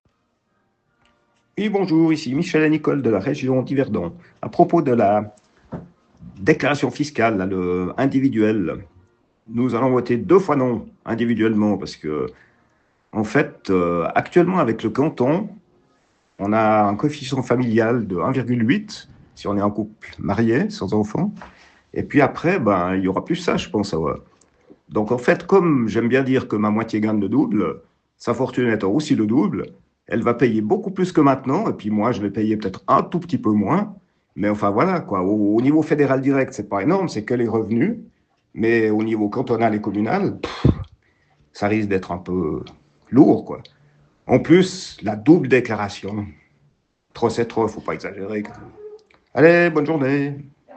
Finalement, écoutons ce qu’en dit cet auditueur anonyme de la matinale sur la première chaîne de notre radio romande…